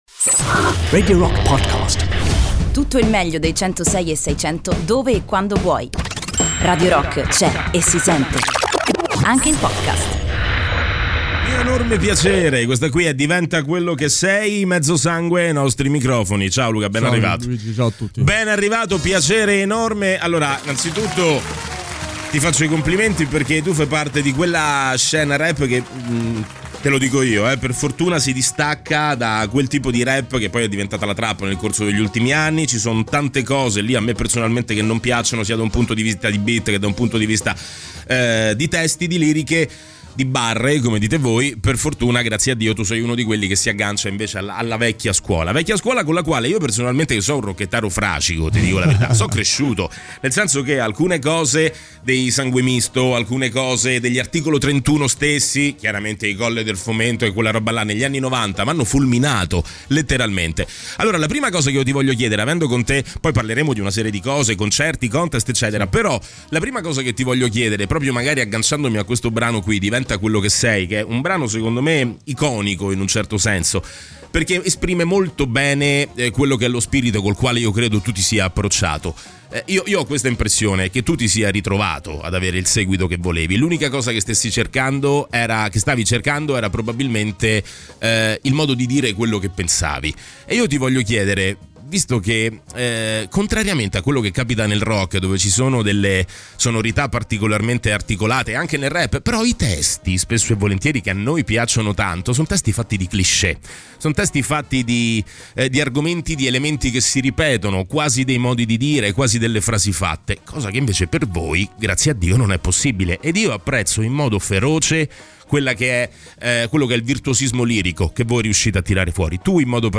"Intervista": Mezzosangue (07-02-20)